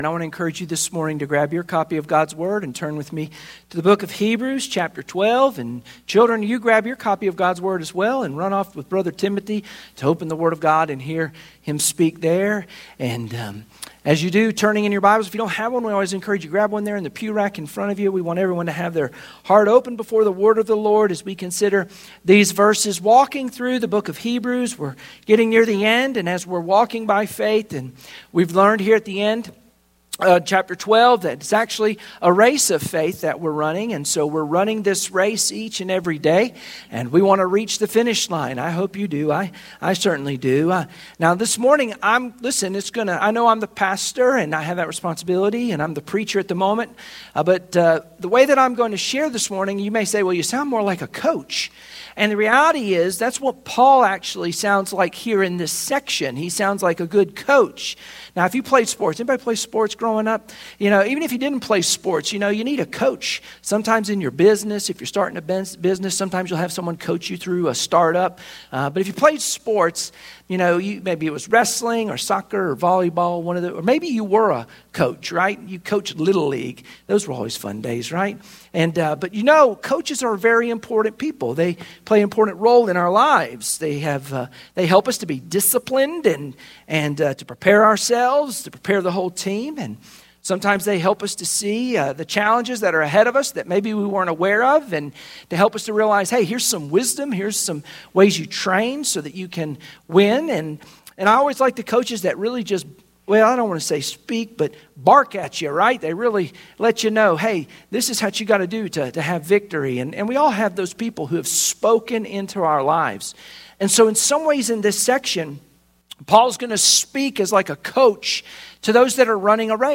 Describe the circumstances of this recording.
Sunday Morning Worship Passage: Hebrews 12:12-17 Service Type: Sunday Morning Worship Share this